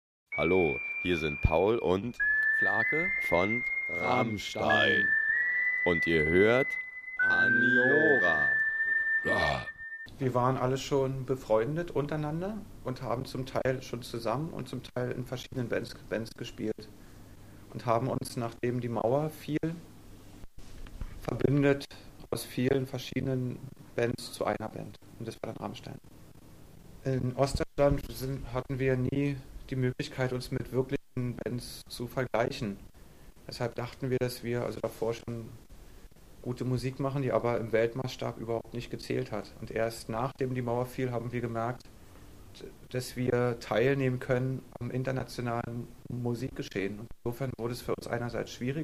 Paul & Flake Interview -
Interview |
Radio_Aniara_Paul_Flake_Interview.mp3